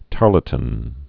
(tärlə-tən, -lə-tn)